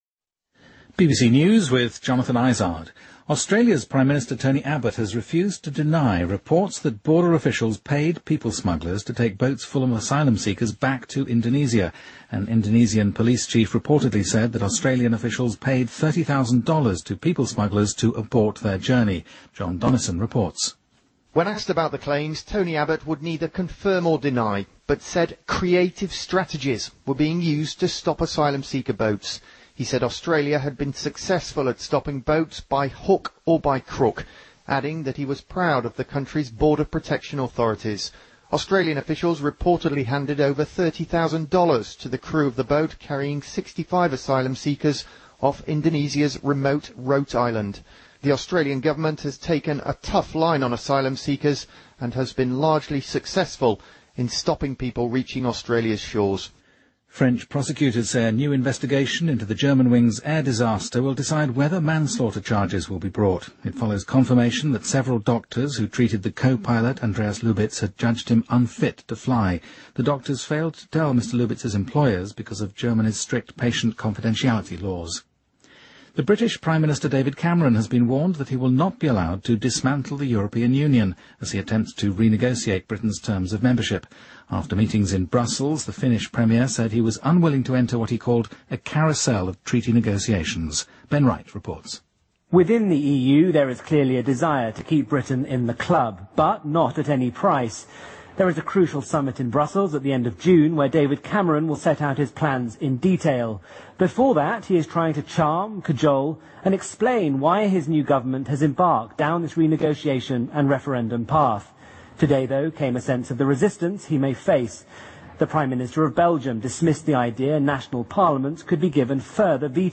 BBC news,卡梅伦推销欧盟改革方案遭拒绝